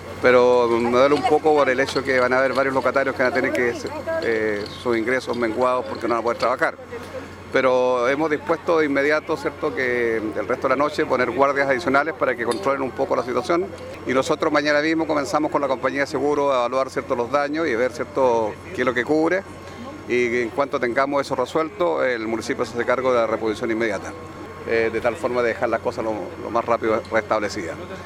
Autoridades locales, encabezadas por el alcalde de la comuna, Jaime Bertín, llegaron también al lugar del incendio, toda vez que el espacio afectado es una construcción municipal. En esa línea, es que el edil osornino señaló que de inmediato se activará un plan para dar solución a los comerciantes afectados.